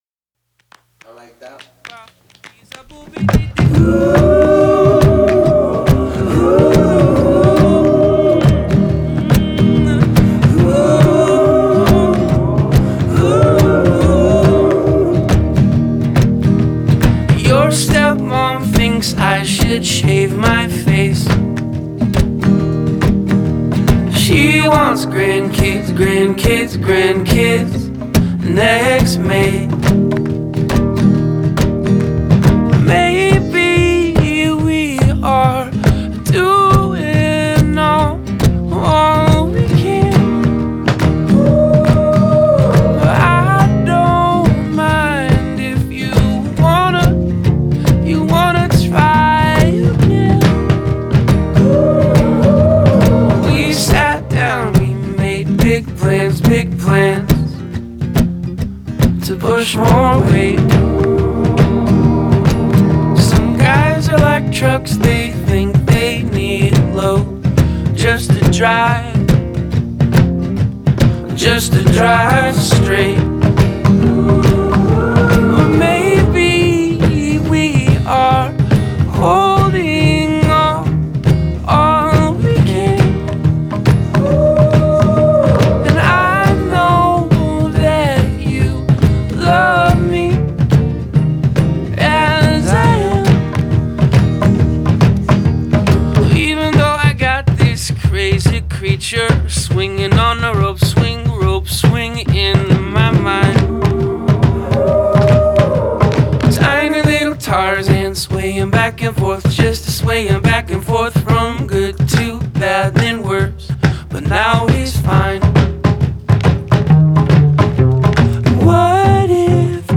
Une folk inventive et superbement arrangée